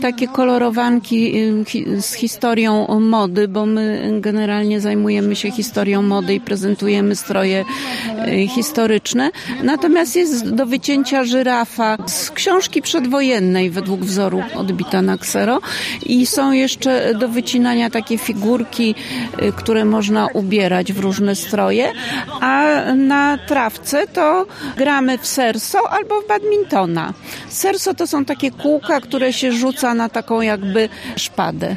W niedzielne popołudnie (05.05.2024 r.) w Dworze Lutosławskich – Muzeum Przyrody w Drozdowie odbył się Piknik Patriotyczny z udziałem dzieci i ich rodzin w ramach Uroczystości Rocznicy Uchwalenia Konstytucji 3 Maja.